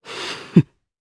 Riheet-Vox_Laugh_jp.wav